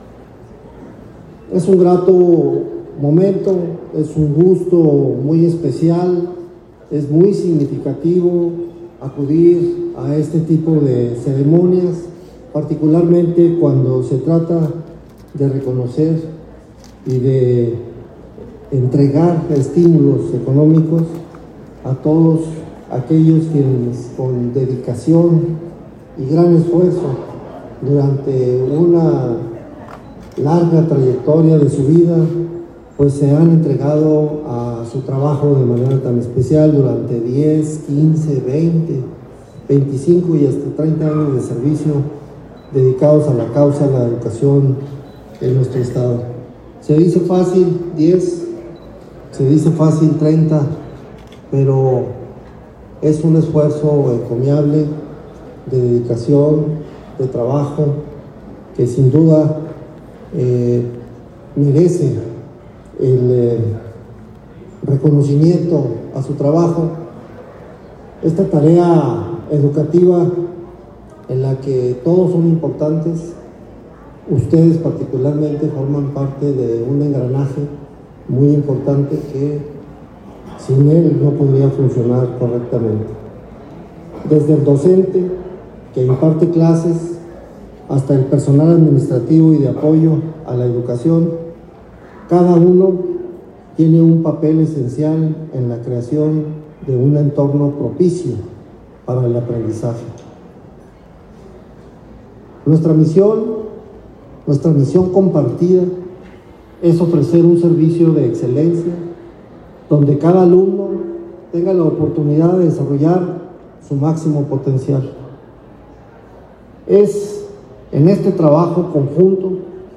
audio_secretario_de_educacion_reconocimiento_seccion_42.mp3